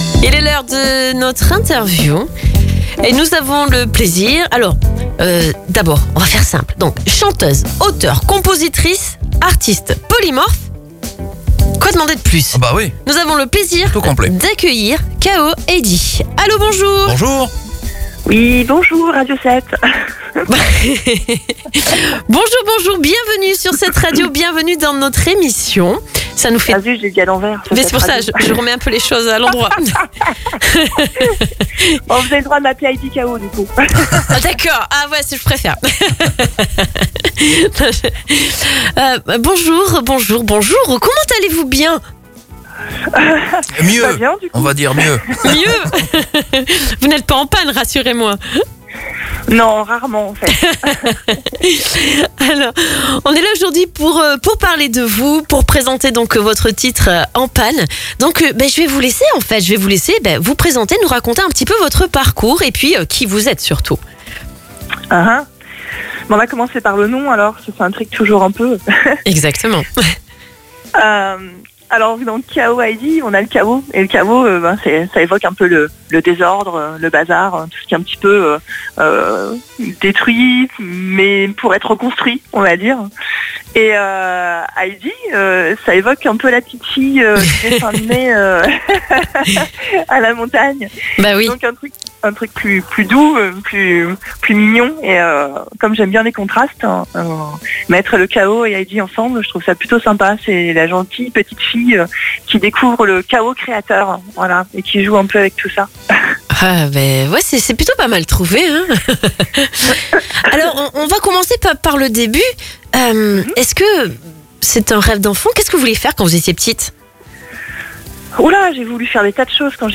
ELECTRO POP